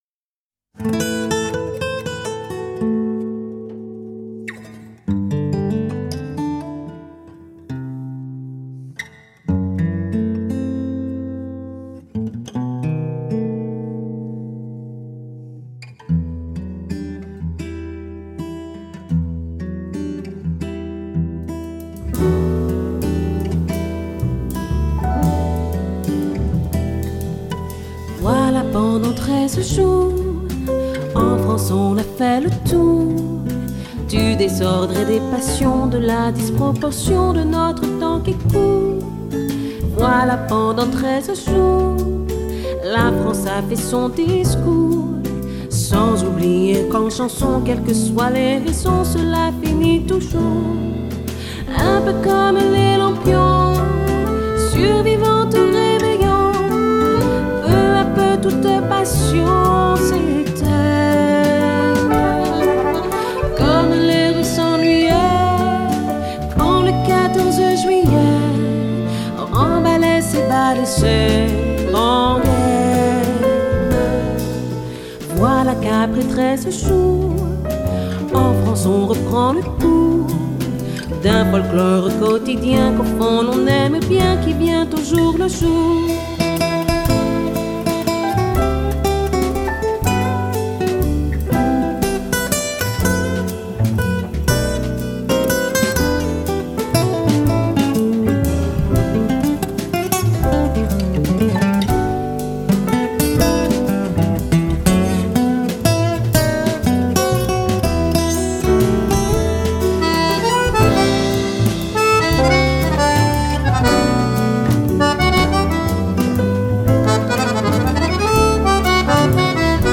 极其优美动听，为同名1968冬奥会记录片主题曲。 此曲一出，立成香颂经典。